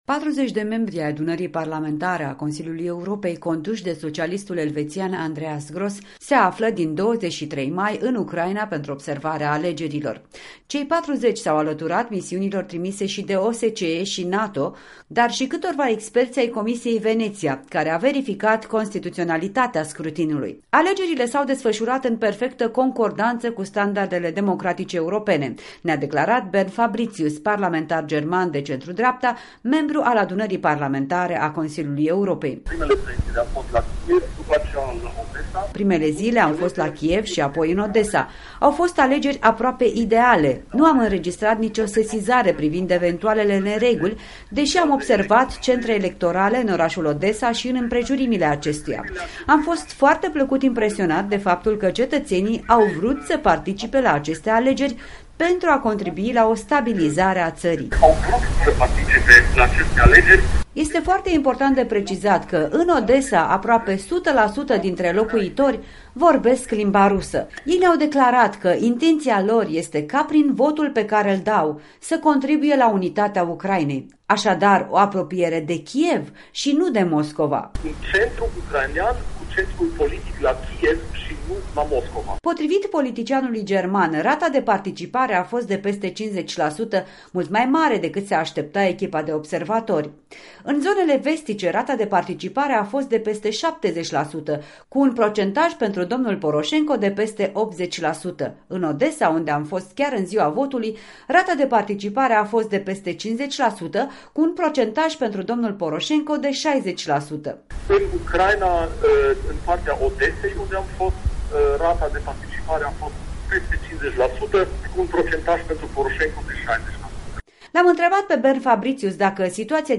În direct de la Strasbourg: un interviu cu europarlamentarul Bernd Fabritius